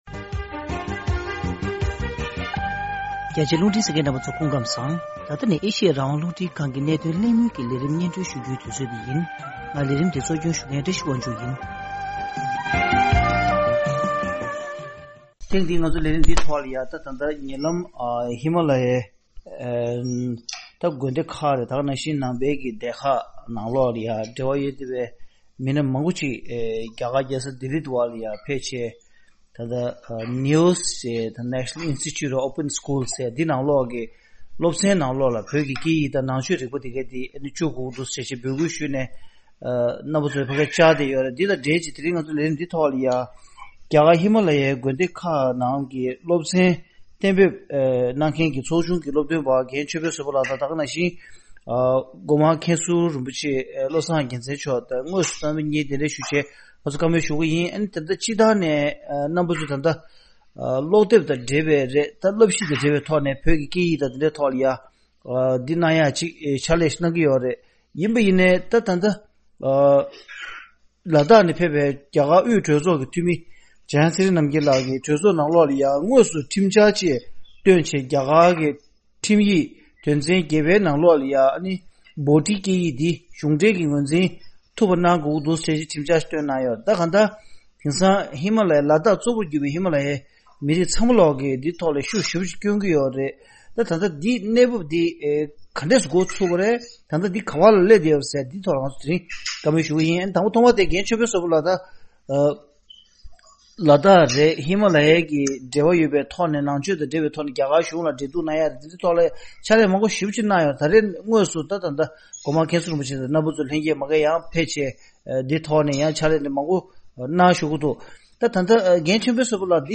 ཐེངས་འདིའི་ གནད་དོན་གླེང་མོལ་གྱི་ལས་རིམ་ནང་།